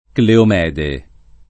Cleomede [ kleom $ de ]